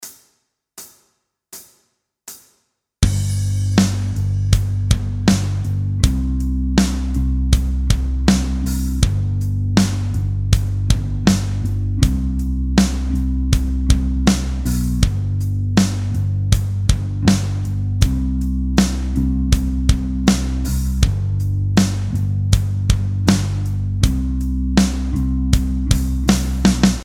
Chord Progression: G – C – G – C
Backing-Track-2-2.mp3